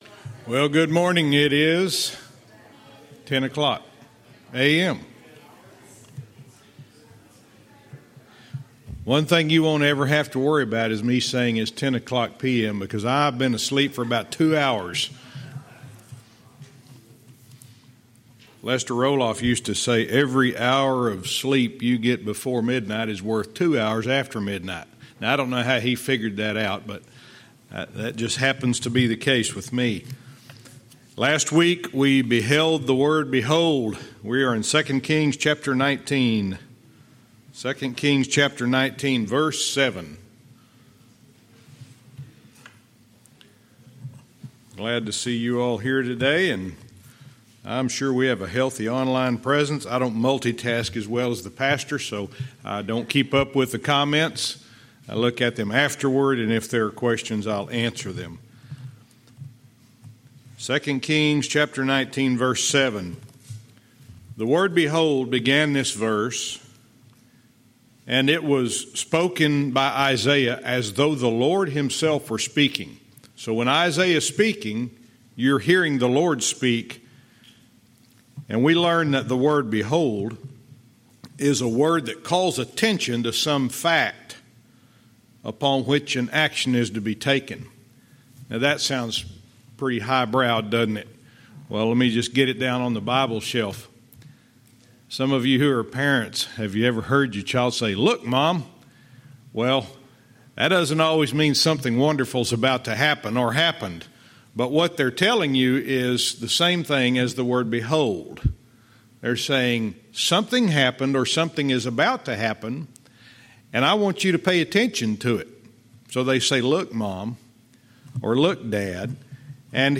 Verse by verse teaching - 2 Kings 19:7(cont)-8